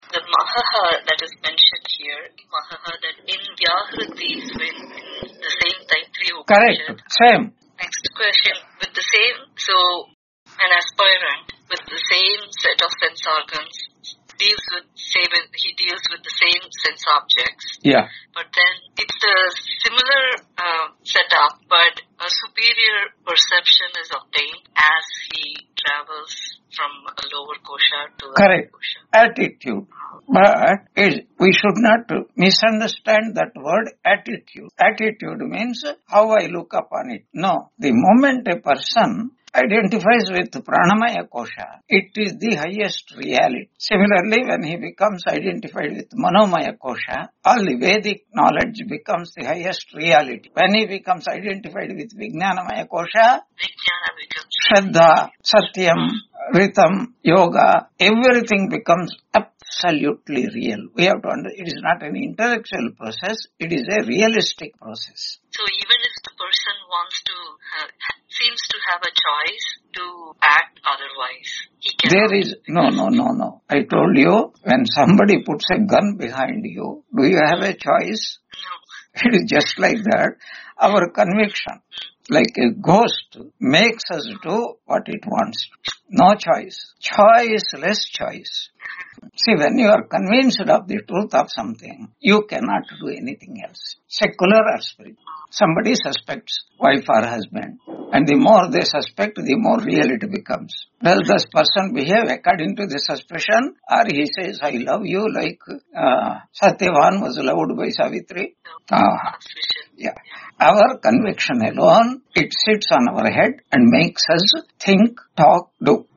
Taittiriya Upanishad Lecture 75 Ch2 4-5.2 on 22 October 2025 Q&A - Wiki Vedanta